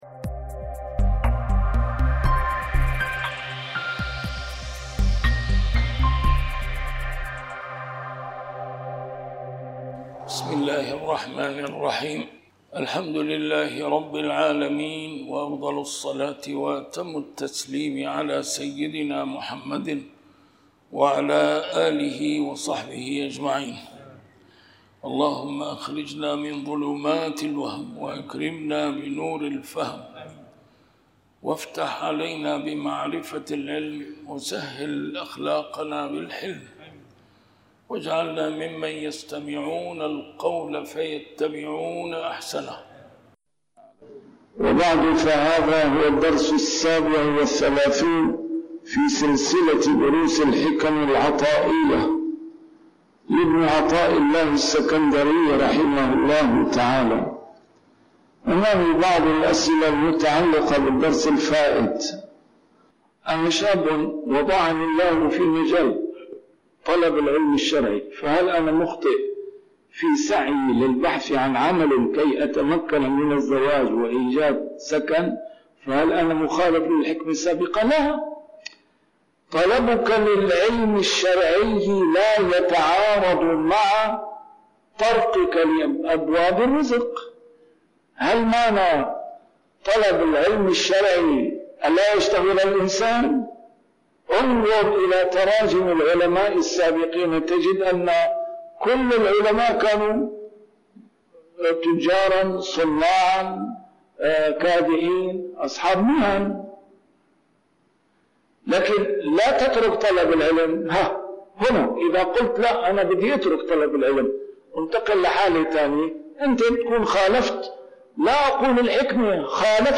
A MARTYR SCHOLAR: IMAM MUHAMMAD SAEED RAMADAN AL-BOUTI - الدروس العلمية - شرح الحكم العطائية - الدرس رقم 37 شرح الحكمة 21